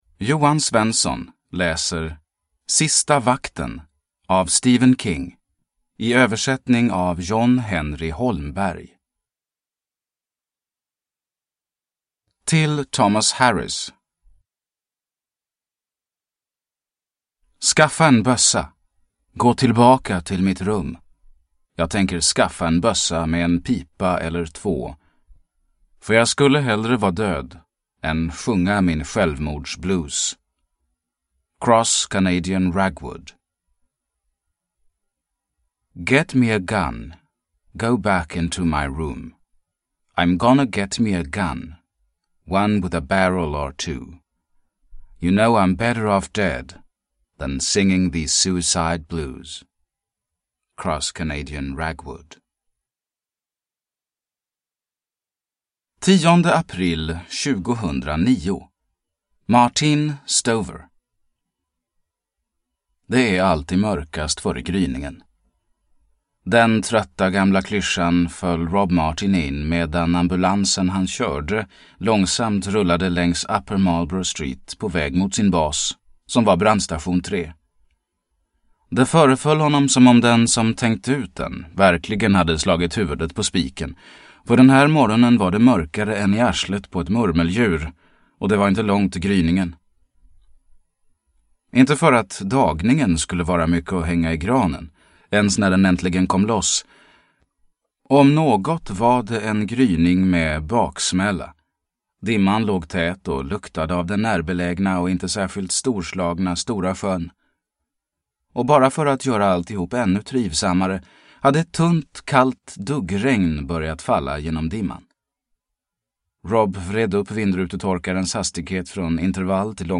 Sista vakten – Ljudbok – Laddas ner